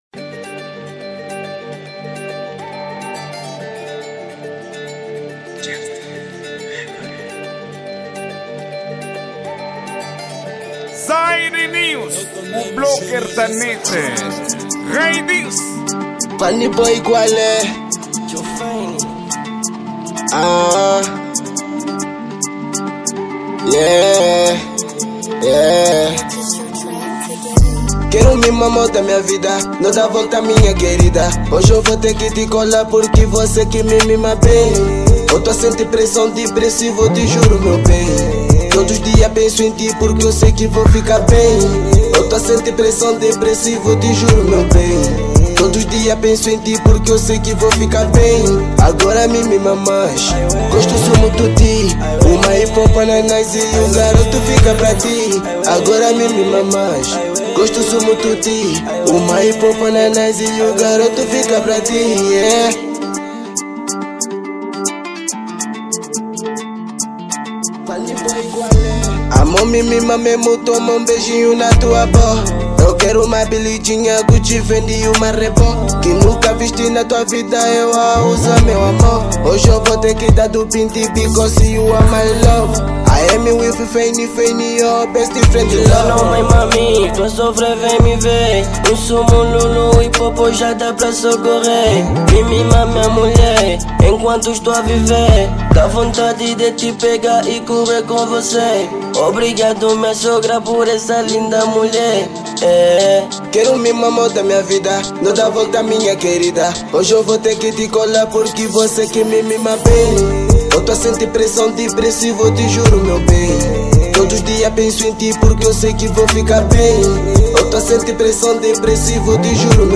Género:Rap